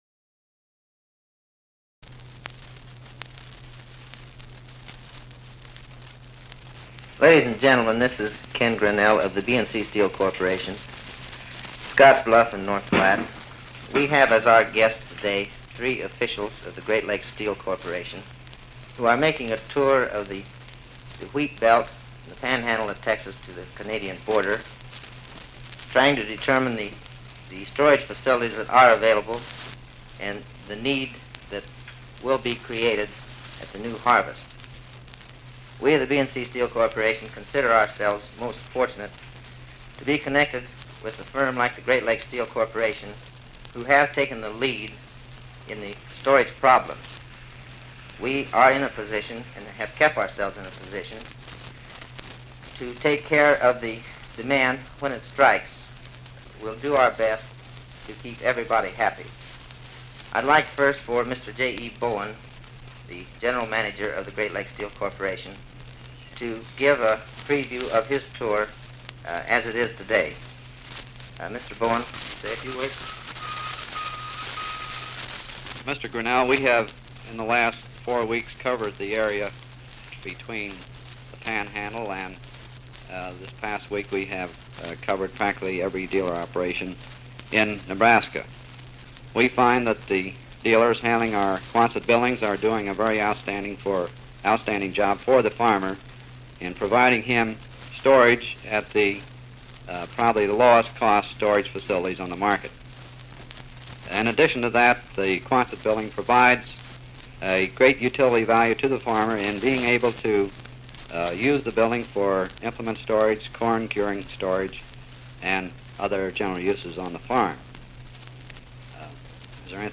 While searching through the company archives, we found this 33 1/3 vinyl containing a 1949 interview